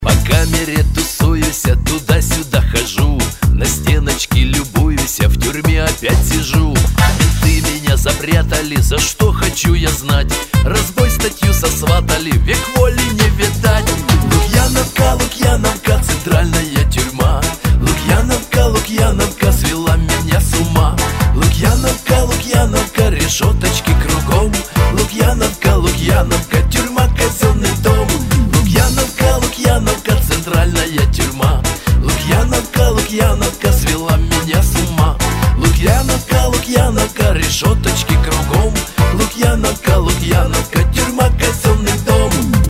Категория: Шансон | Дата: 10.12.2012|